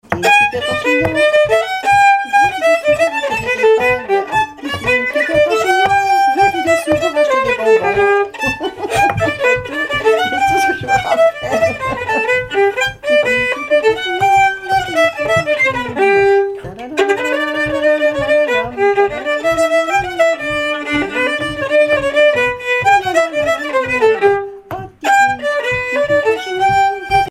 gestuel : danse
Pièces instrumentales à plusieurs violons
Pièce musicale inédite